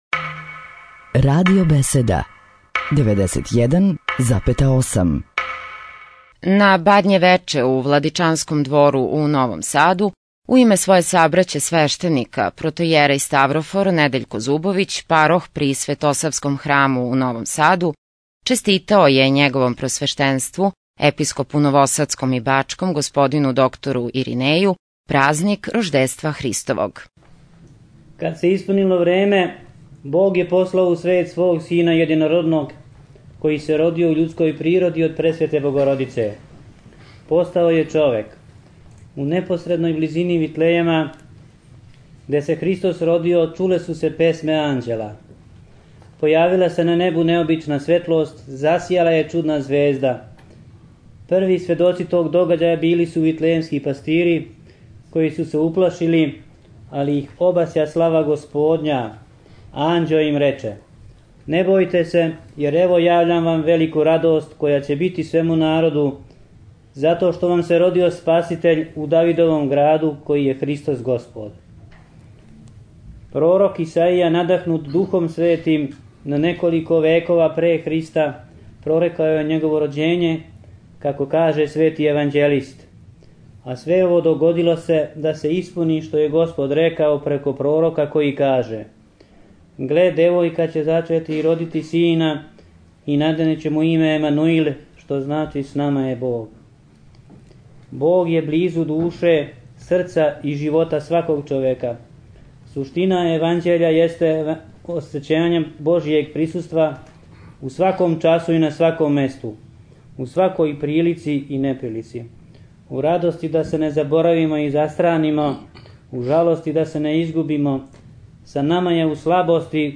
Нови Сад